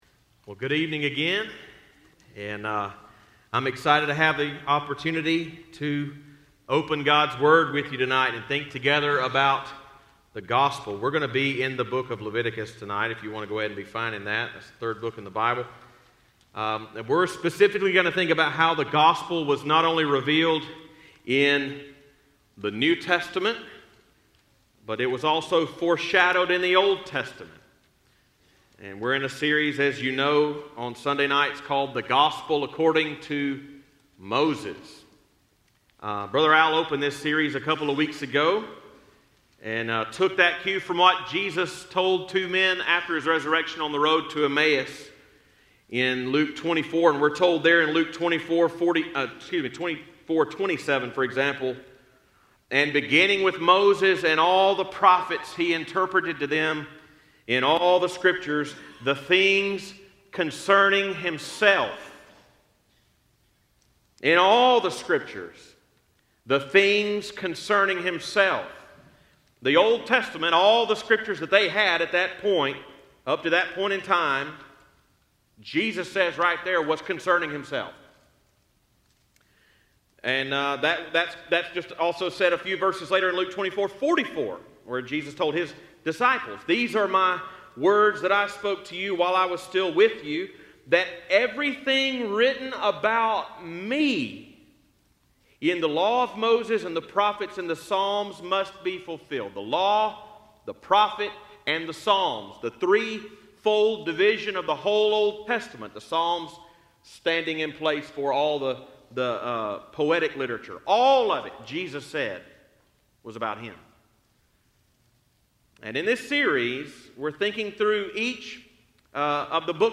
Leviticus 16 Service Type: Sunday Evening 1.